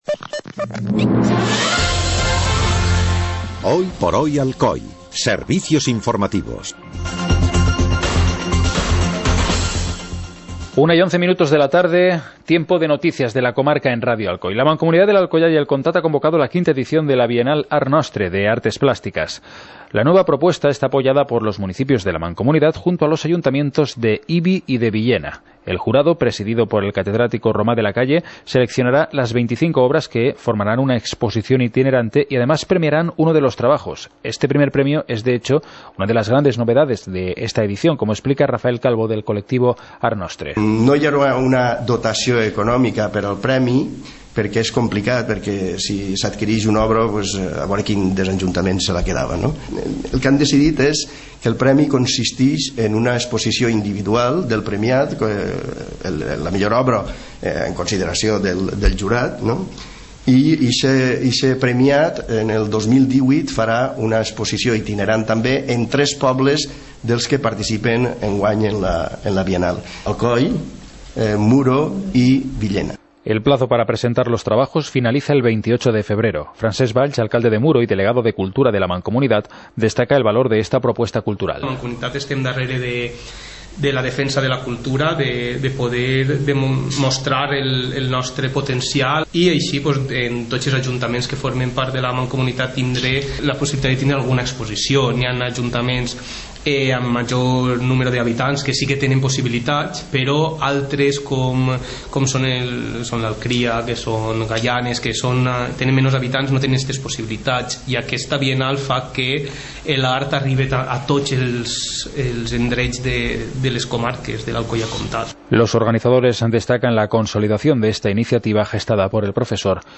Estas escuchando: Informativo comarcal - jueves, 12 de enero de 2017 Informativo comarcal - jueves, 12 de enero de 2017 Tu navegador no soporta este reproductor de audio.